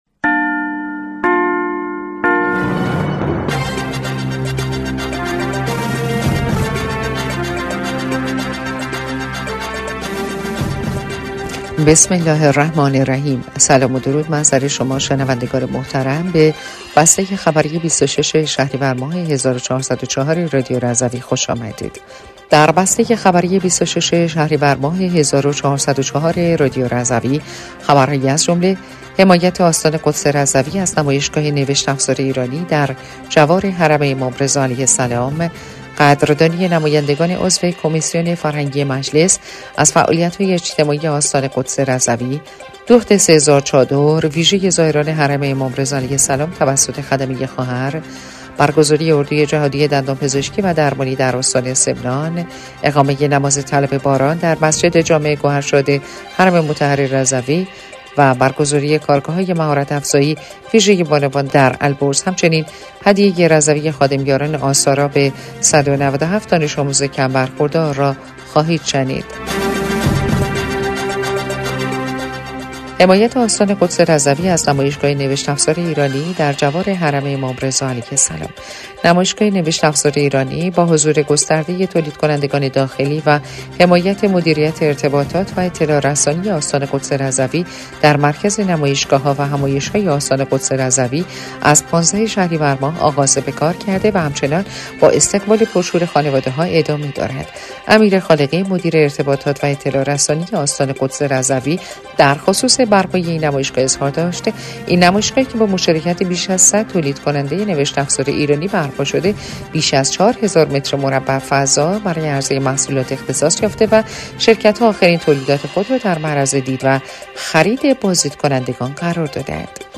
بسته خبری ۲۶ شهریور ۱۴۰۴ رادیو رضوی/